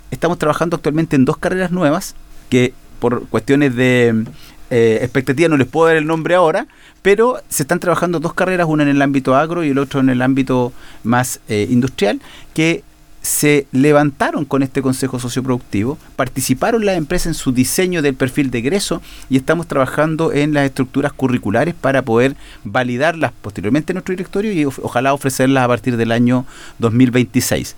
En conversación con Radio Bio Bio